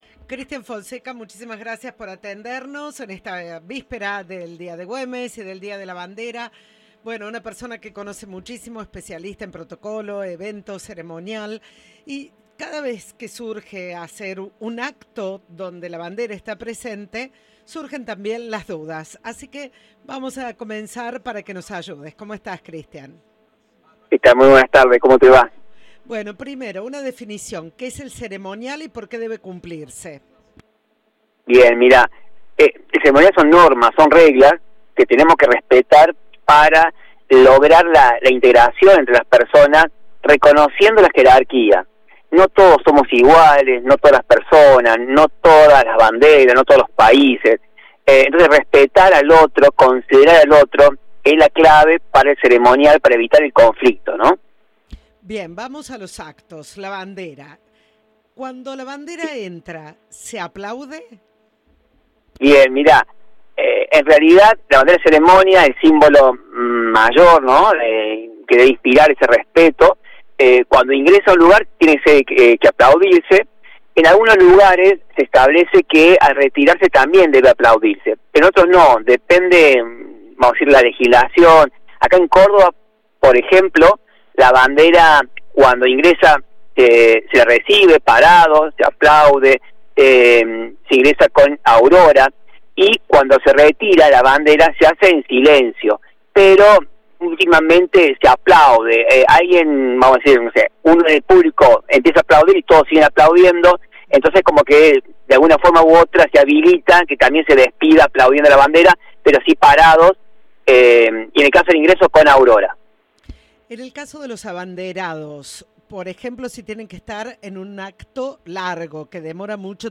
Entrevista de "Turno Noche".